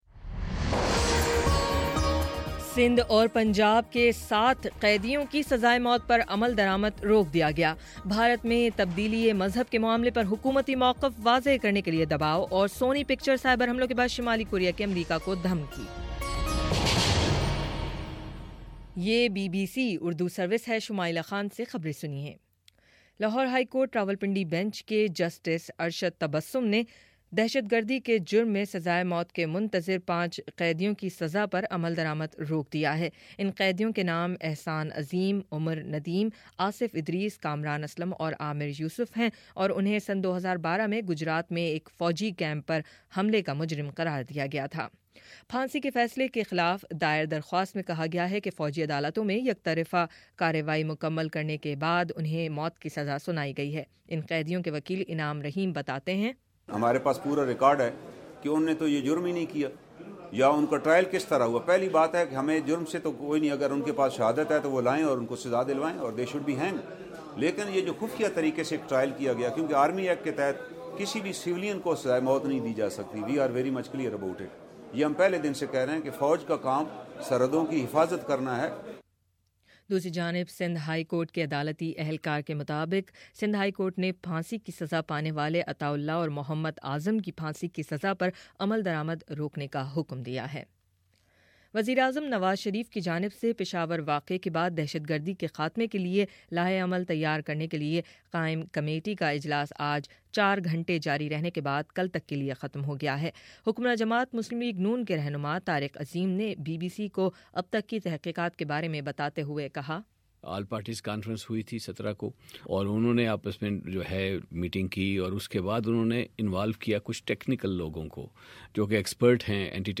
دسمبر22: شام چھ بجے کا نیوز بُلیٹن